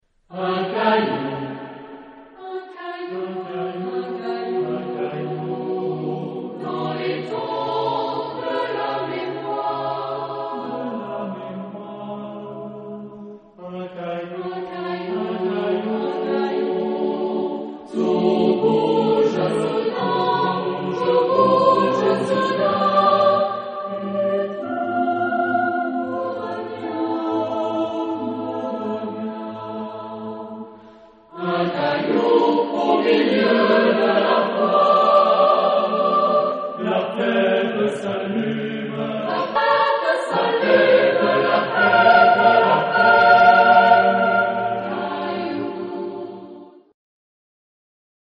Genre-Style-Form: Contemporary ; Secular ; Partsong
Type of Choir: SATB  (4 mixed voices )
Tonality: D minor ; F sharp minor